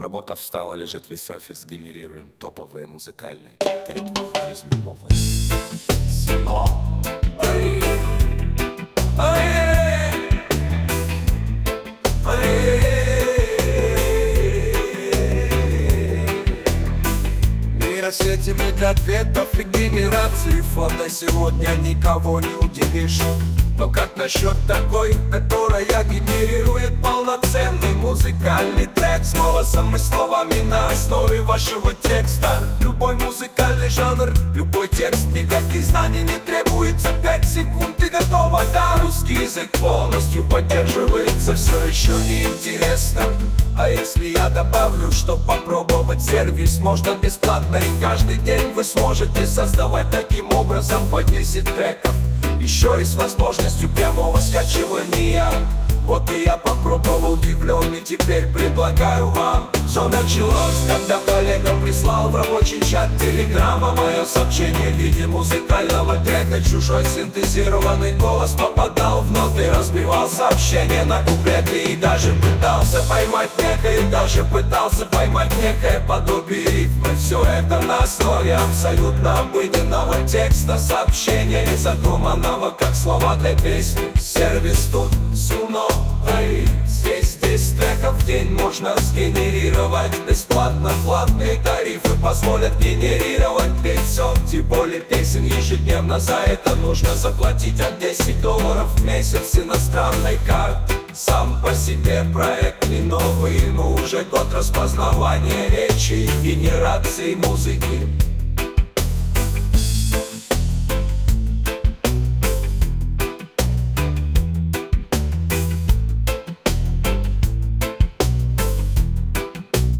Чужой синтезированный голос попадал в ноты, разбивал сообщение на куплеты и даже пытался поймать некое подобие рифмы.
Голос в этом примере не фонтан, сервис может лучше.